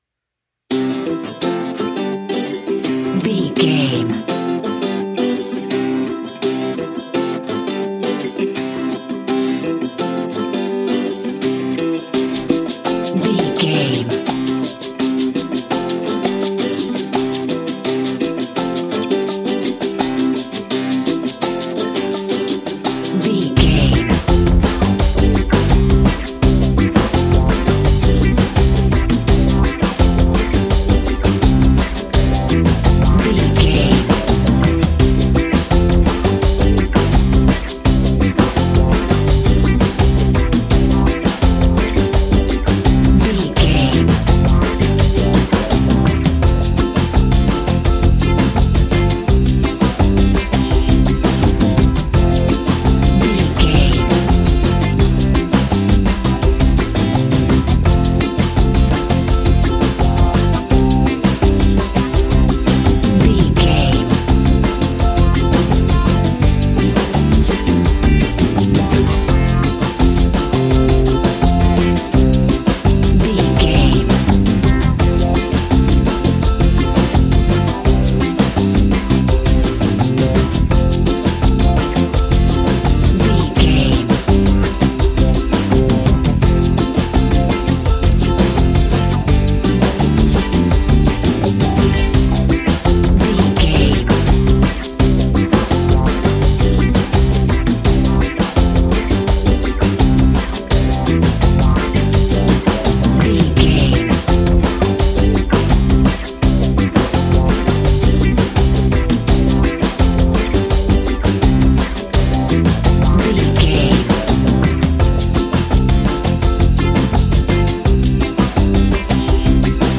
Ionian/Major
cheerful/happy
mellow
fun
drums
electric guitar
percussion
horns
electric organ